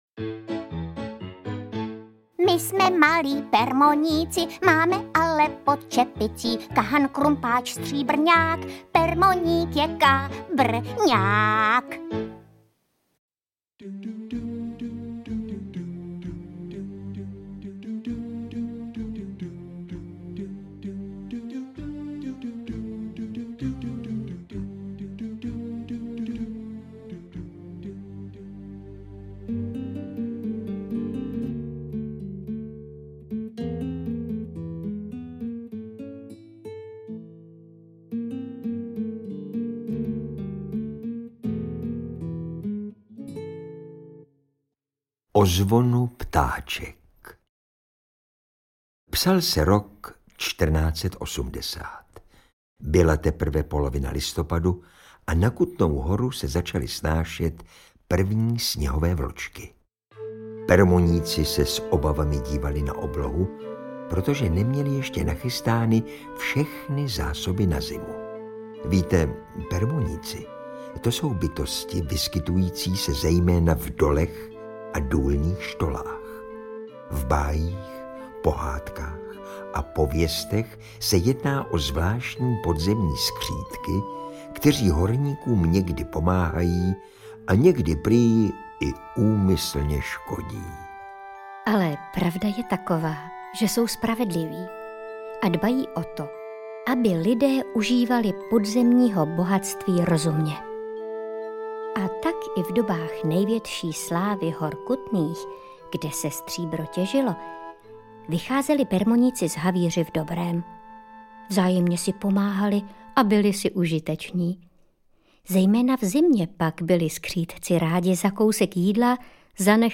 Interpreti:  Eva Hrušková, Jan Přeučil
Pohádky ze stejnojmenné knížky vyprávějí Jan Přeučil a Eva Hrušková.